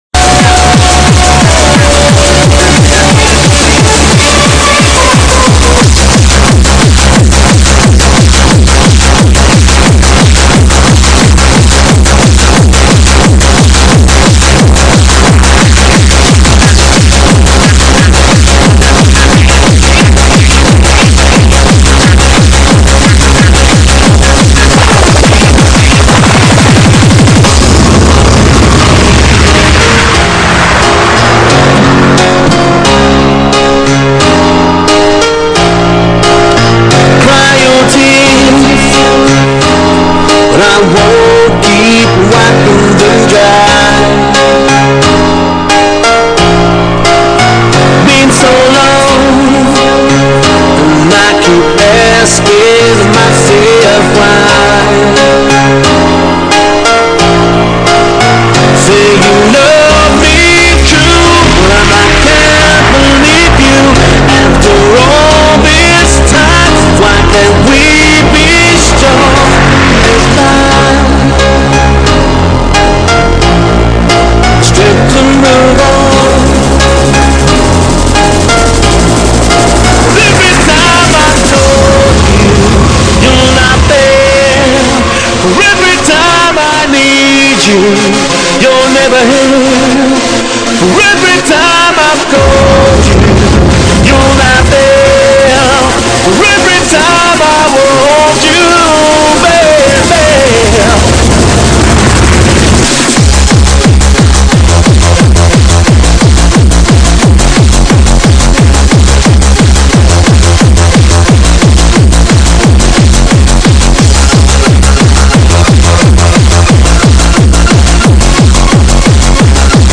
LIVE all around the world